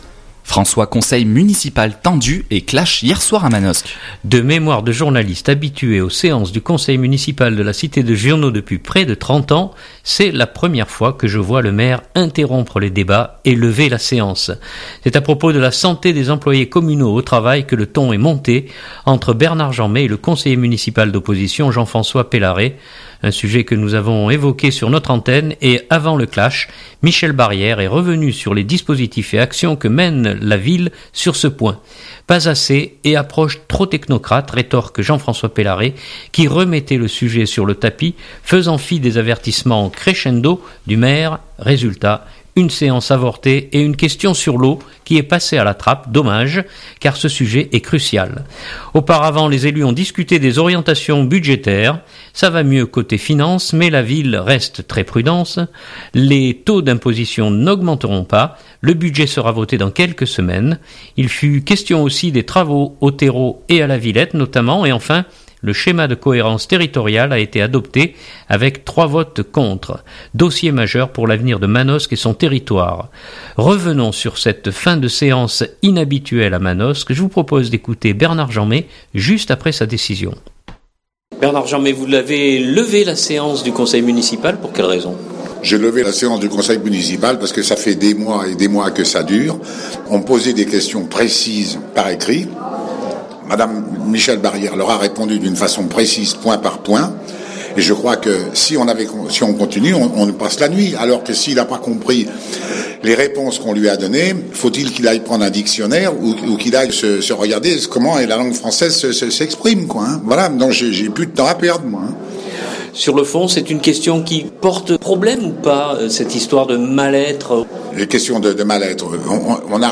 Je vous propose d’écouter Bernard Jeanmet juste après sa décision.